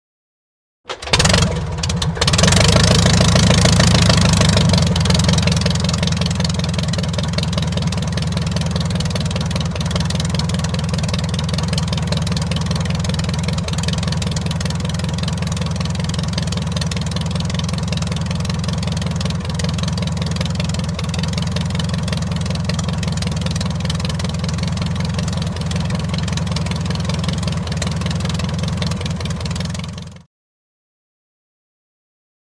Prop Plane; Idle; Fokker Single Engine Prop Aircraft Circa 1914, Start Up After Many Attempts And Motor Idling.